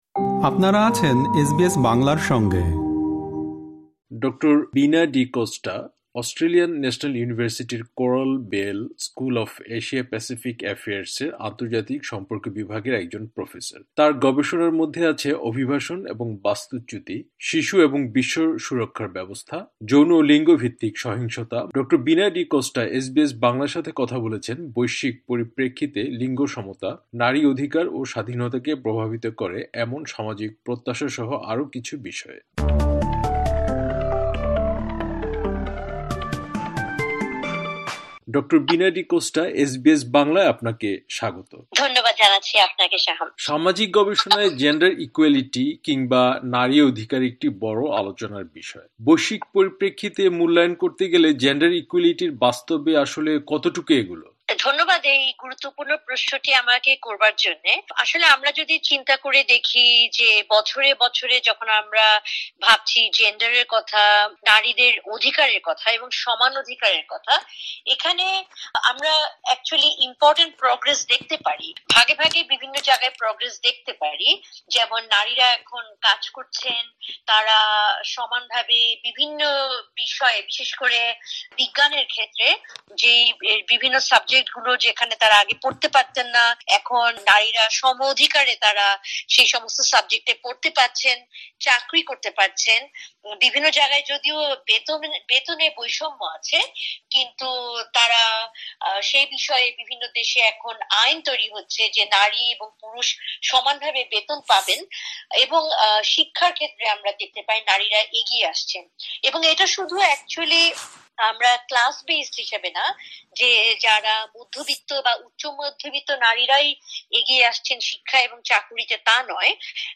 একটি সাক্ষাতকার দিয়েছিলেন।